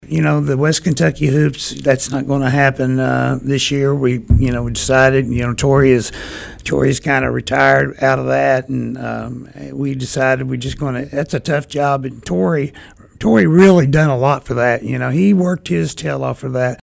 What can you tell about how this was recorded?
on The Sideline Report on WPKY Radio Thursday morning